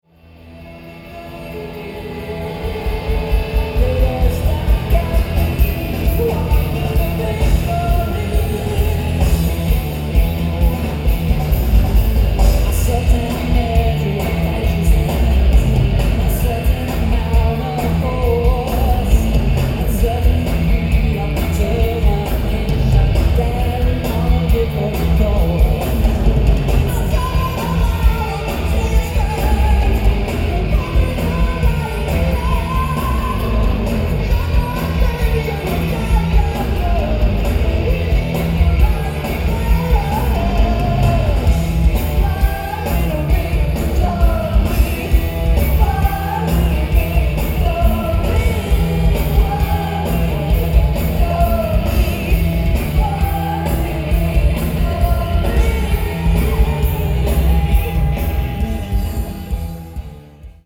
Source: Audience (Master DAT)
Venue: Montage Mountain Amphitheater
Equipment List: Sony PCM-M1, Soundman OKM II RKS mics
Recorded fourth row, a little to stage right of Alex.
Sound samples (shitty mp3 but they will have to do: